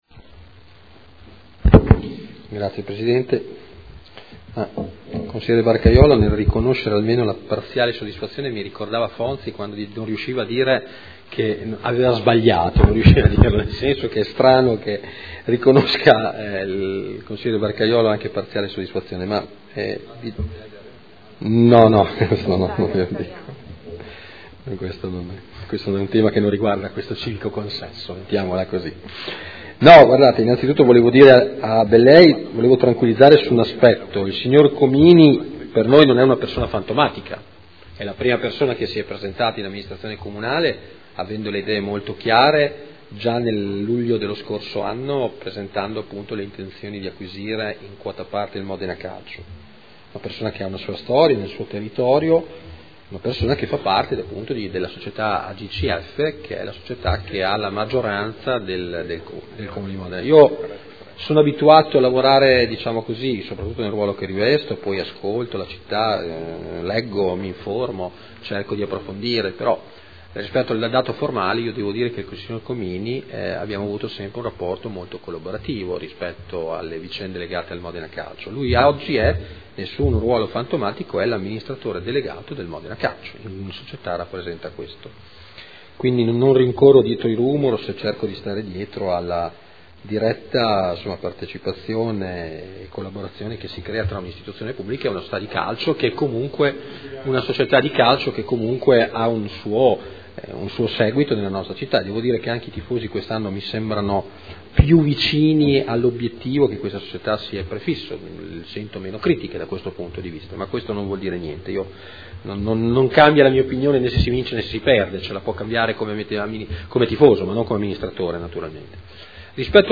Antonino Marino — Sito Audio Consiglio Comunale
Seduta del 19/09/2013. Conclude dibattito su interrogazione del consigliere Barcaiuolo (Fratelli d’Italia-Centrodestra Nazionale) avente per oggetto: “Modena F.C.”